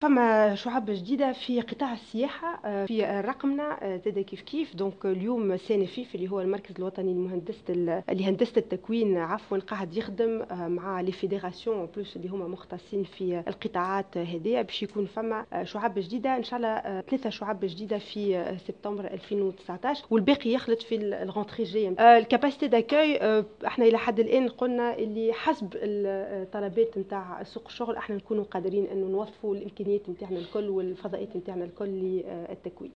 وأضافت في تصريح لمرسلة الجوهرة اف ام على هامش انعقاد لجنة قيادة الاستراتيجية الوطنية للتشغيل في دورتها الثالثة اليوم الخميس، إن الشعب الجديدة ستنطلق بداية من سبتمبر 2019، مشيرة إلى أن الوزارة تعمل على إحداث شعب أخرى للمواسم الدراسية المقبلة.